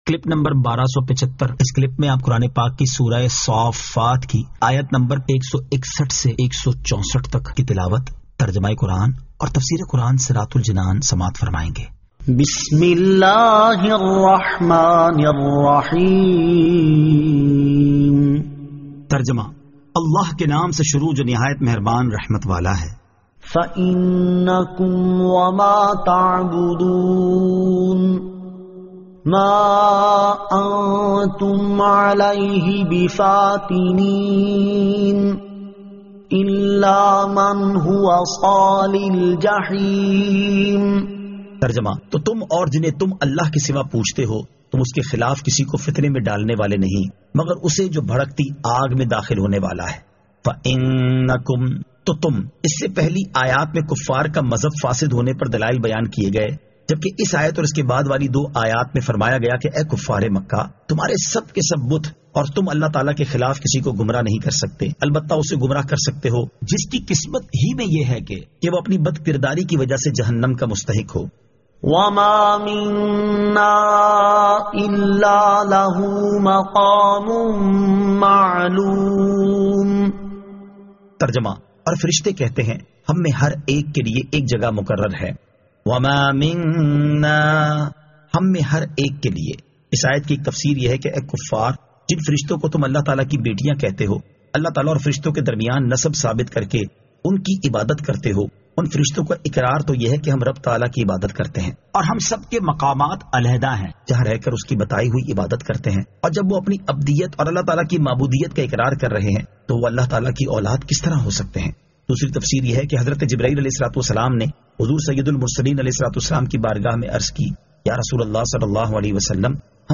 Surah As-Saaffat 161 To 164 Tilawat , Tarjama , Tafseer